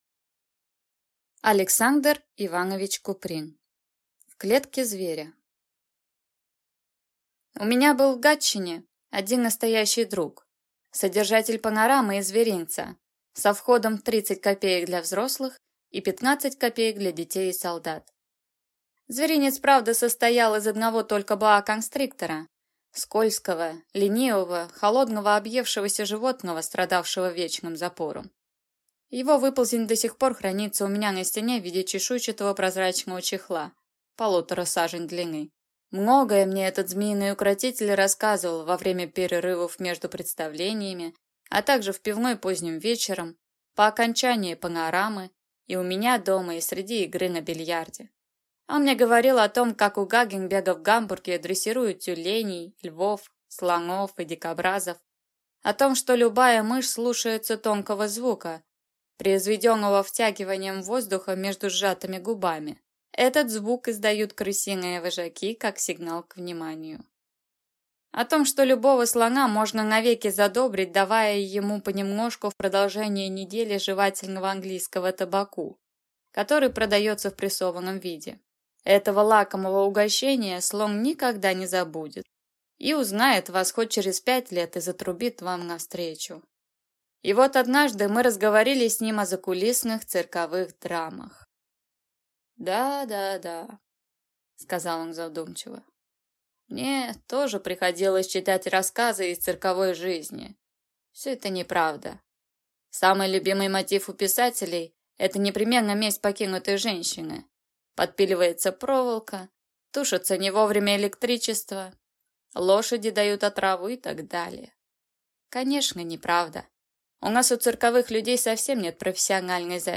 Аудиокнига В клетке зверя | Библиотека аудиокниг